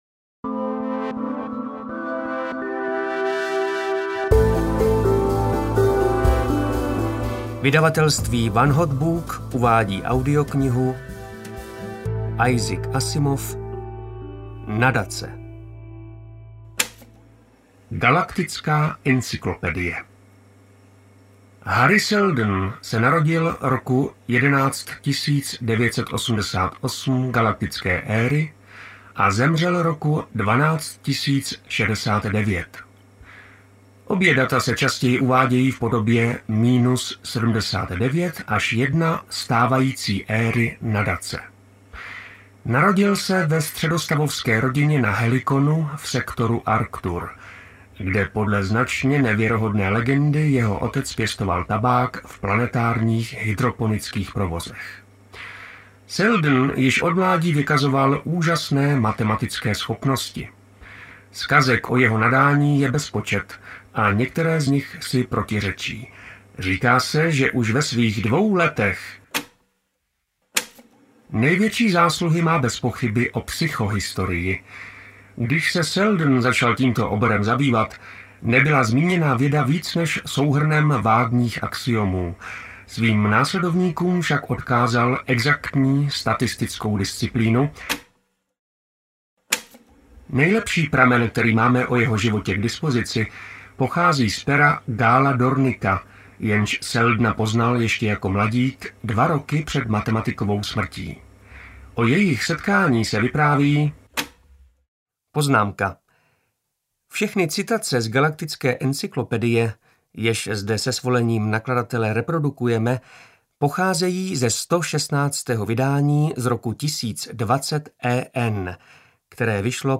Nadace audiokniha
Ukázka z knihy